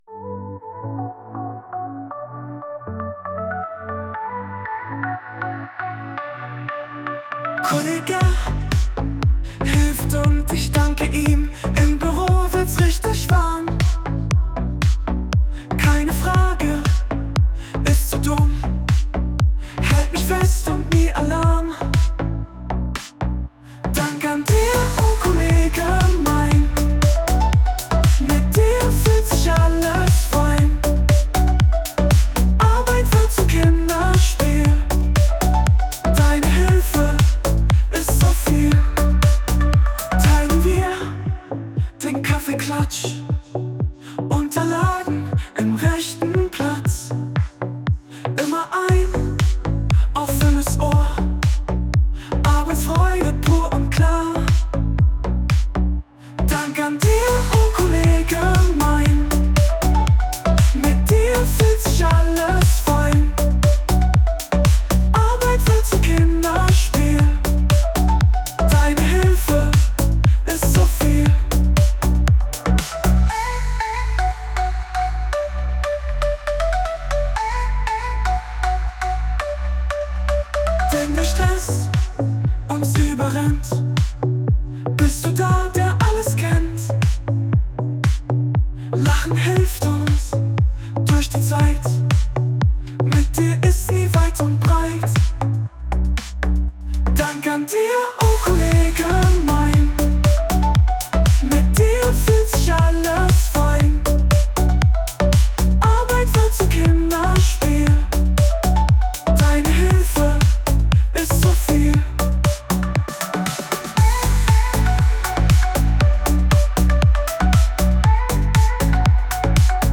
Music Created by AI Song Generator of Style melodic pop
melodic pop